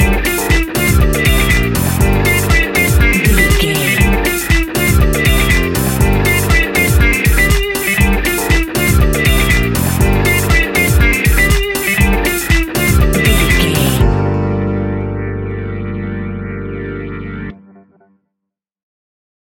Aeolian/Minor
energetic
hypnotic
groovy
drums
bass guitar
electric guitar
electric piano
disco house
electro funk
upbeat
synth leads
Synth Pads
synth bass
drum machines